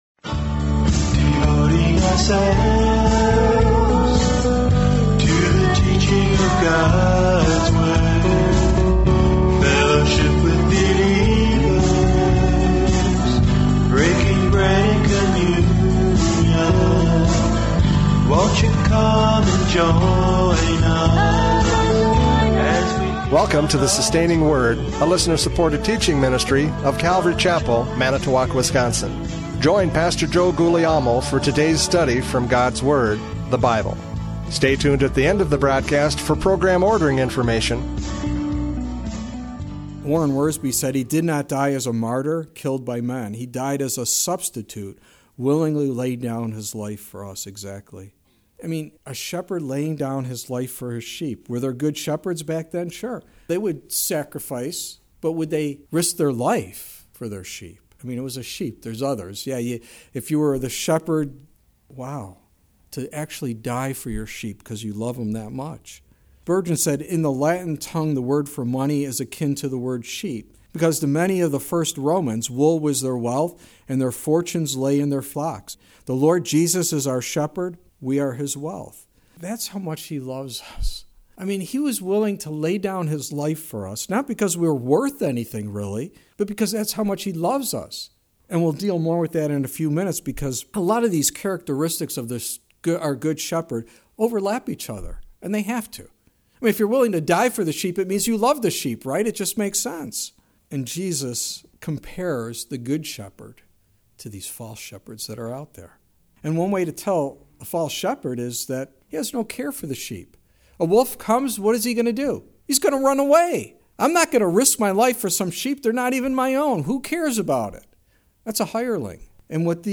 John 10:11-21 Service Type: Radio Programs « John 10:11-21 The Good Shepherd!